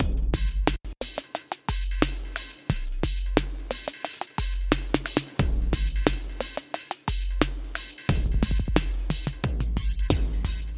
Tag: 89 bpm Hip Hop Loops Drum Loops 1.81 MB wav Key : Unknown